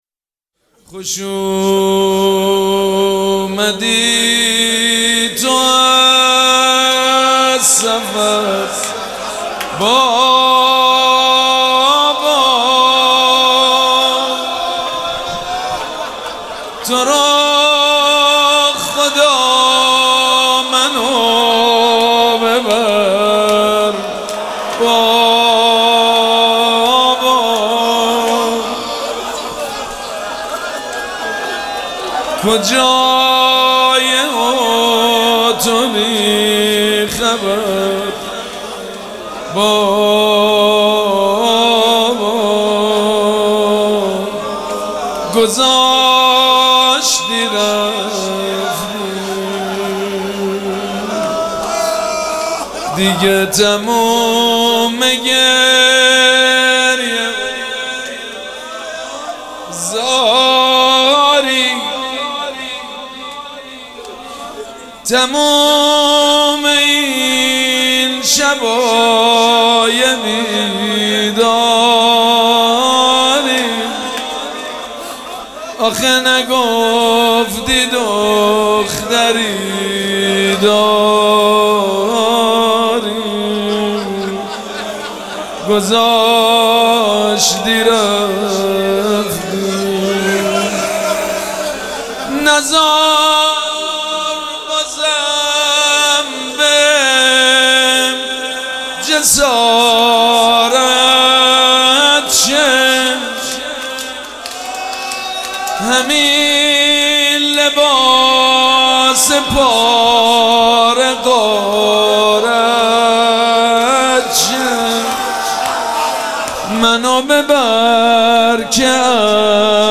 مراسم عزاداری شب شهادت حضرت رقیه سلام الله علیها
روضه
حاج سید مجید بنی فاطمه